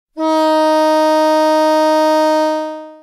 Sirene Longo Alcance e Evacuação